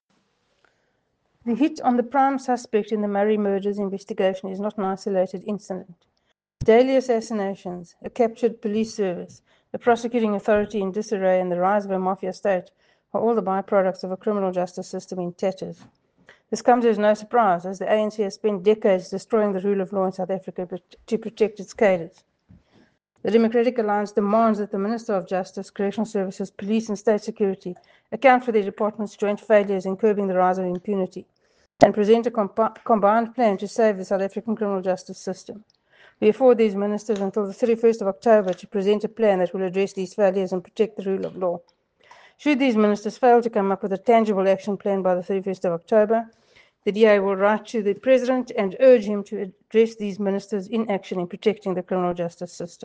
Issued by Adv. Glynnis Breytenbach MP – DA Spokesperson on Justice and Constitutional Development
Soundbite by Adv. Glynnis Breytenbach MP.